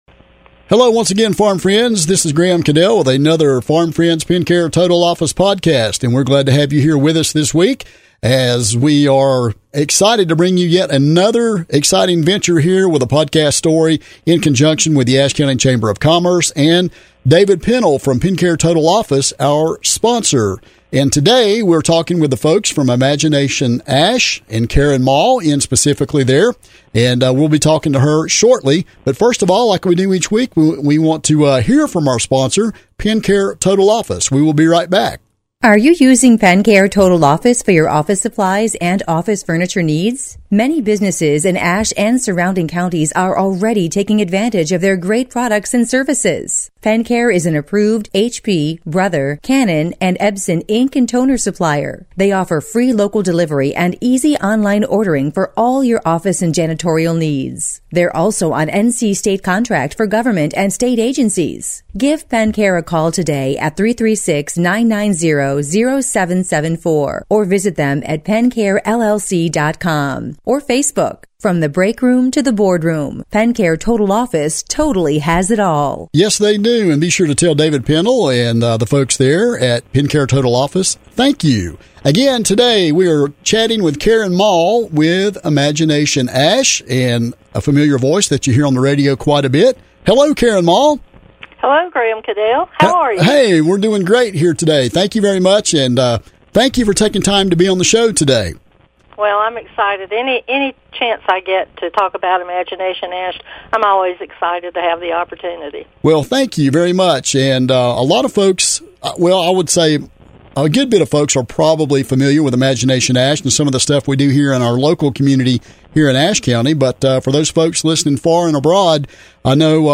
WKSK Podcast Interview